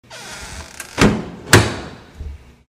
جلوه های صوتی
دانلود صدای در 10 از ساعد نیوز با لینک مستقیم و کیفیت بالا
برچسب: دانلود آهنگ های افکت صوتی اشیاء دانلود آلبوم صدای باز و بسته شدن درب از افکت صوتی اشیاء